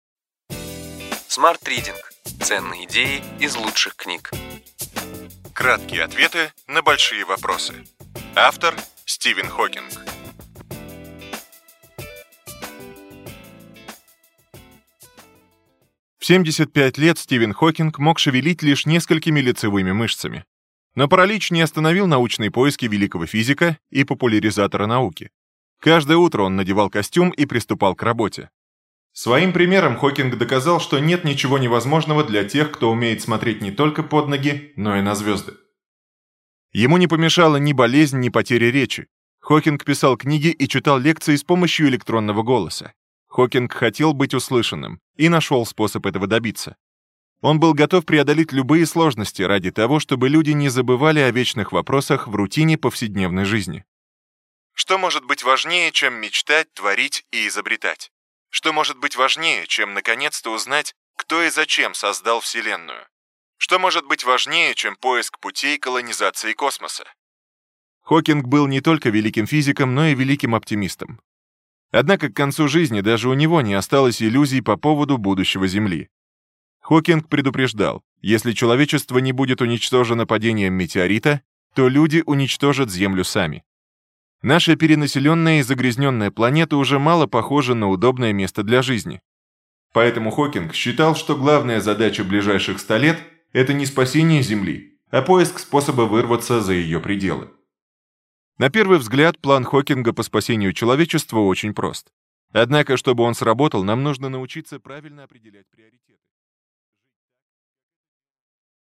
Аудиокнига Ключевые идеи книги: Краткие ответы на большие вопросы. Стивен Хокинг | Библиотека аудиокниг